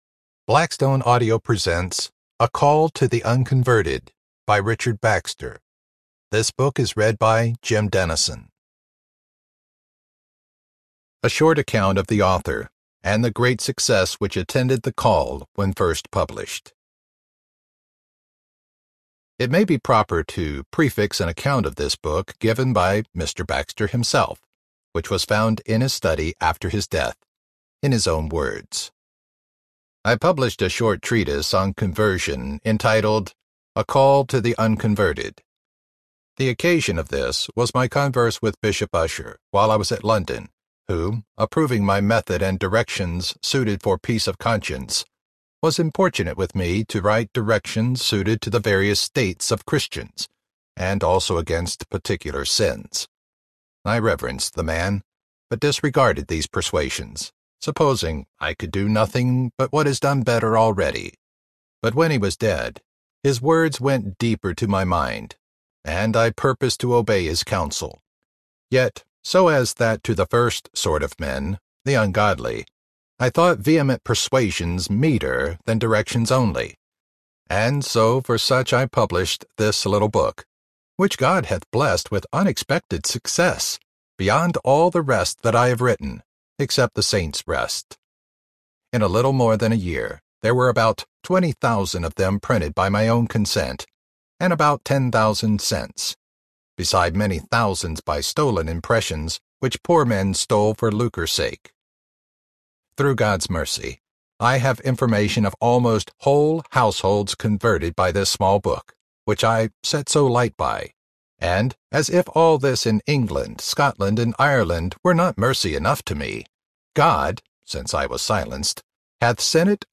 A Call to the Unconverted Audiobook
5.5 Hrs. – Unabridged